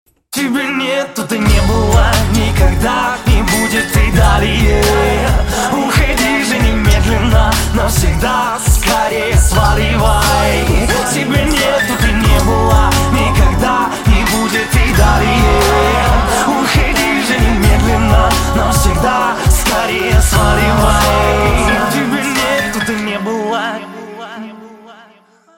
• Качество: 320, Stereo
мужской вокал
русский рэп
злые
грубые